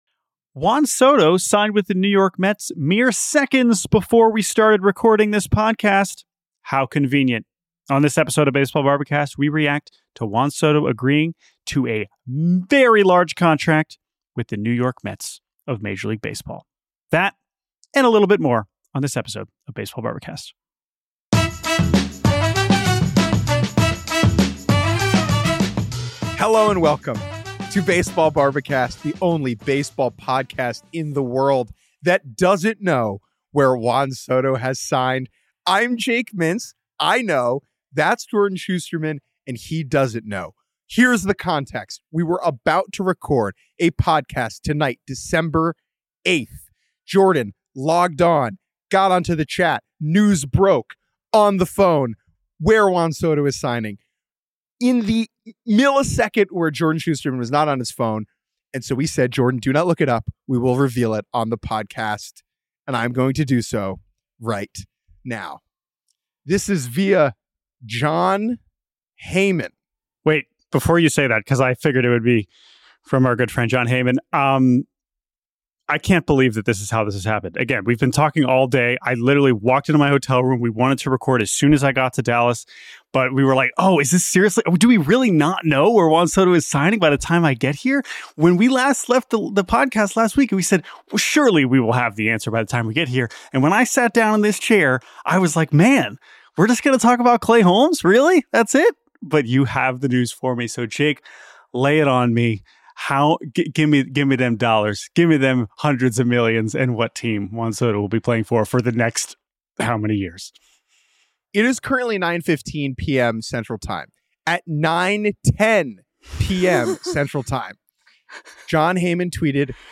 live from the MLB Winter Meetings in Dallas